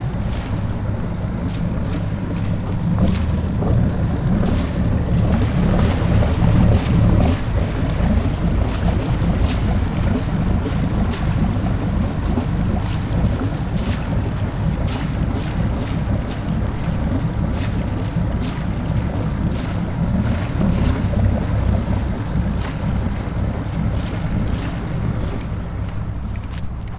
• small fishing boat motor near a harbor.ogg
small_fishing_boat_motor_near_a_harbor_b3m.wav